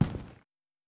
Plastic footsteps
plastic1.wav